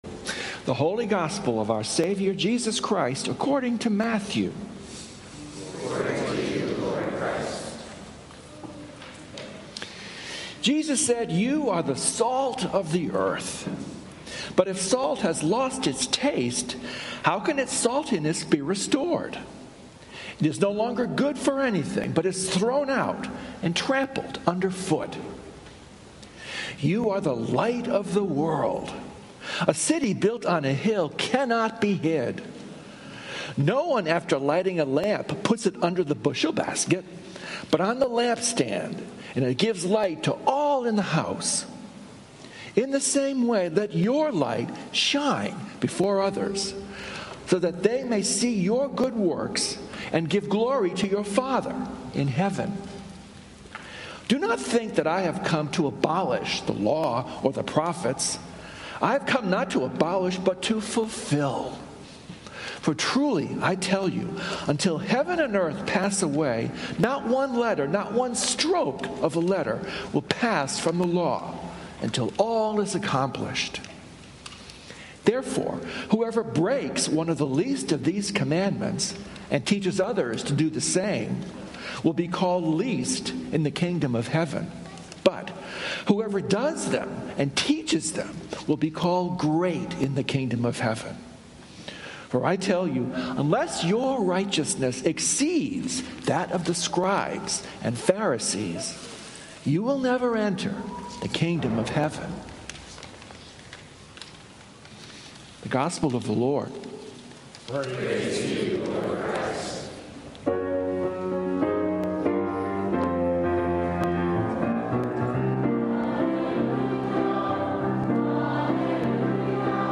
Sunday Sermon
Sermons from St. Columba's in Washington, D.C.